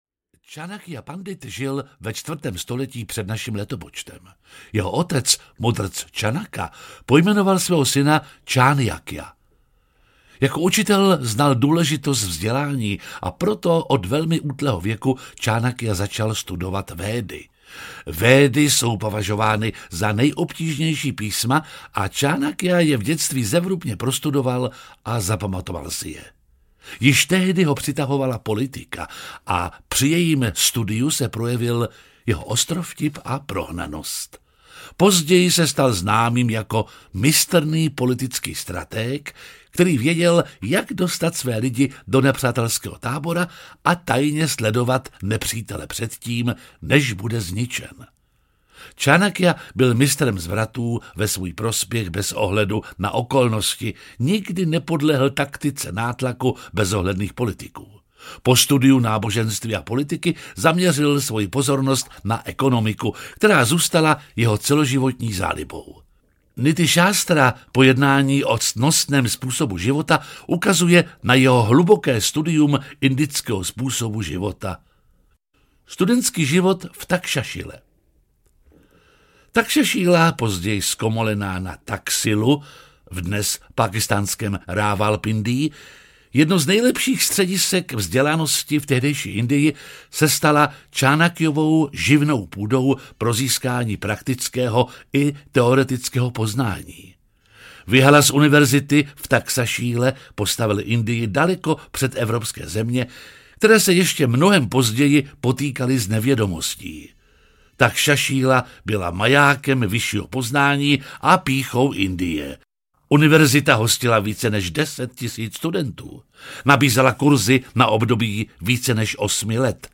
Niti-šástra audiokniha
Ukázka z knihy
• InterpretJiří Lábus